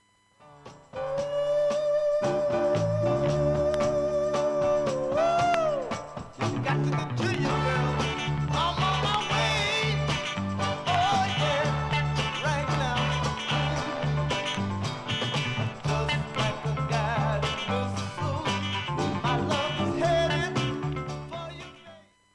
ソウルファルセットを多用する リードボーカルに、 柔らかくしなやかなコーラスが 絡む様が実に心地良い作品